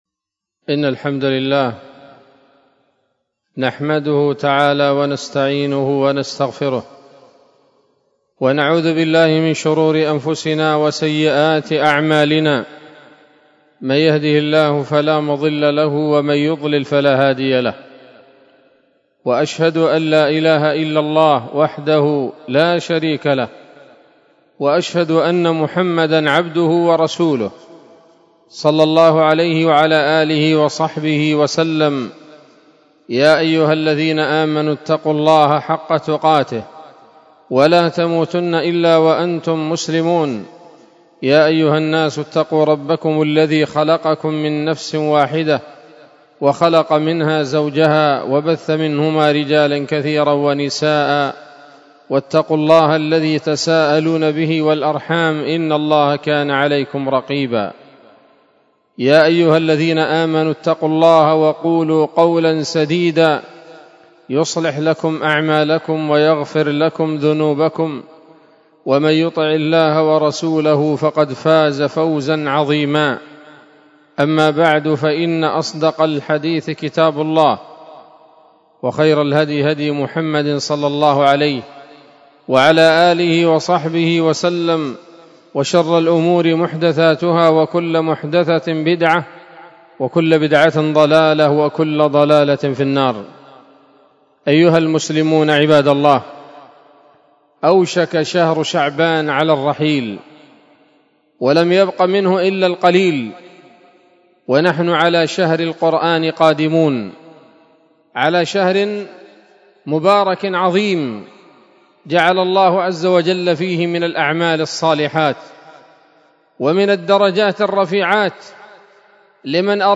خطبة جمعة بعنوان: (( إكرام الرحمن لأهل القرآن )) 22 شعبان 1446 هـ، دار الحديث السلفية بصلاح الدين